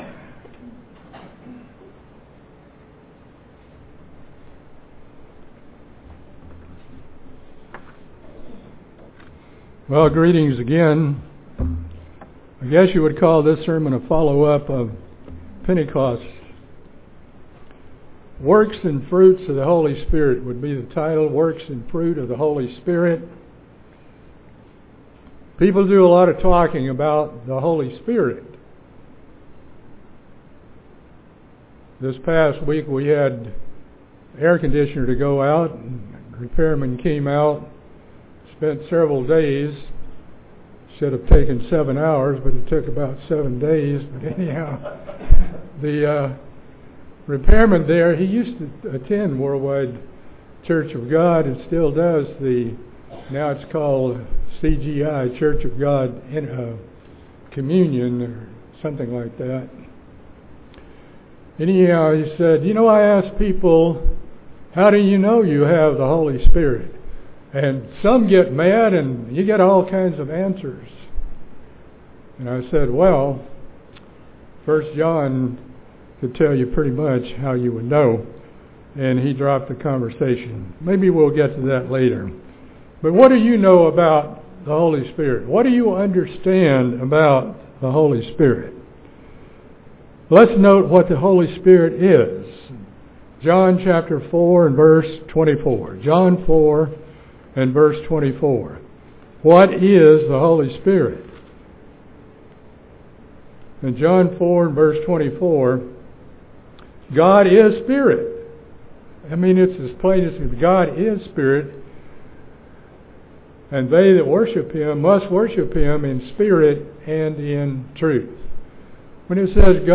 This sermon is about the works and fruits of the Holy Spirit, and our part in keeping the Spirit alive in us by our conduct,study, and obedience.